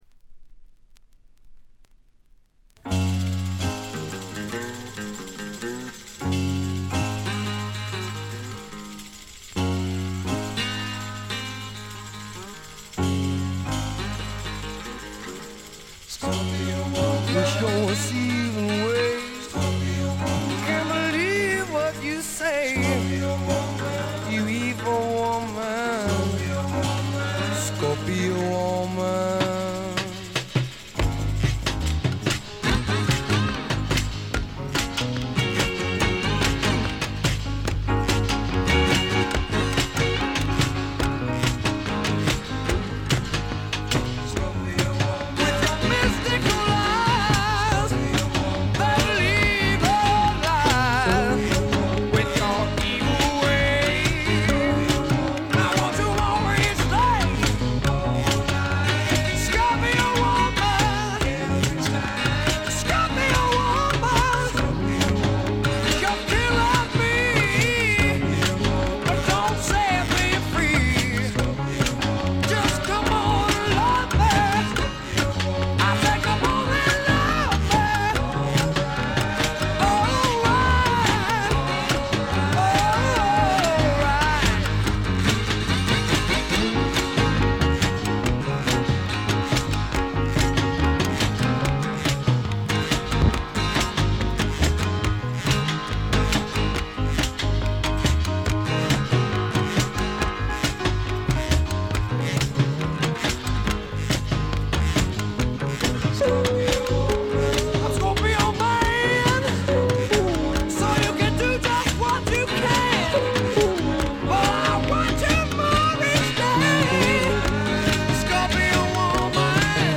軽度のバックグラウンドノイズ、ところどころでチリプチ。
最小限の編成が織り成す、ねばつくような蒸し暑いサウンド。
試聴曲は現品からの取り込み音源です。